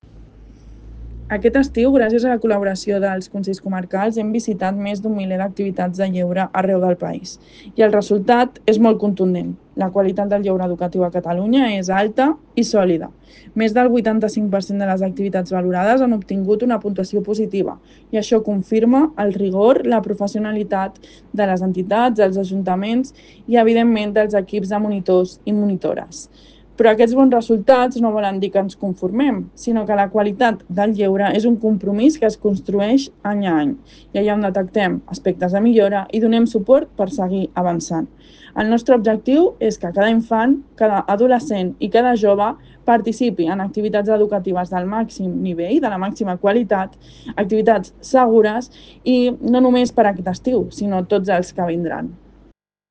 Nota de veu de la directora general de Joventut
nota de veu Clara Quirante.ogg